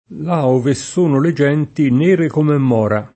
moro [m0ro] s. m. («gelso») — con -o- chiuso la pn. originaria (lat. morus con -o- lungo), da tempo immemorabile passata in tutta Tosc. e in altre regioni a una pn. aperta, così in moro come in mora nome del frutto, per attraz. di moro «scuro» con -o- aperto dall’origine (lat. maurus), attraz. bene spiegabile con un antico paragone spesso ripetuto: là ove sono le genti nere come mora [